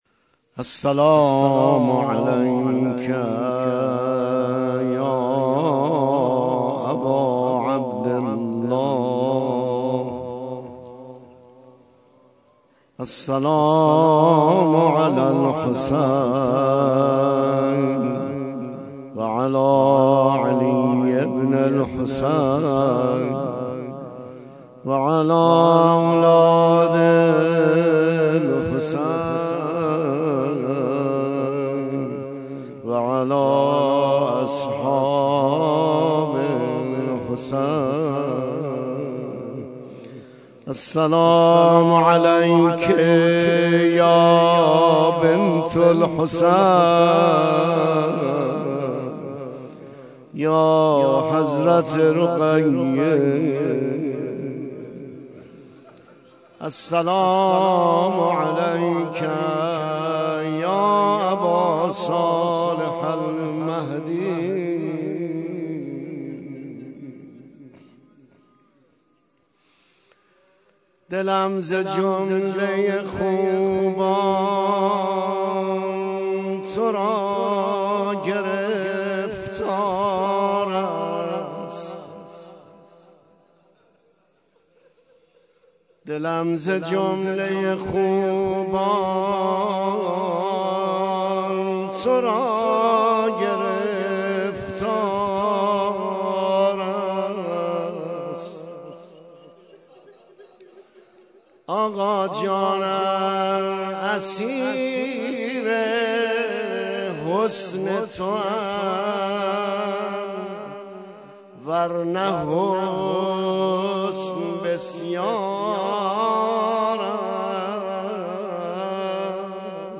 شب سوم محرم
روضه زمینه